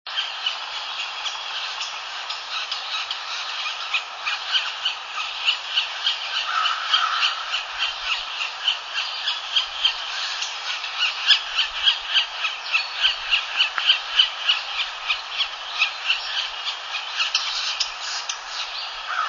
sapsuckers_yellow-bellied_with_nestlings_840.wav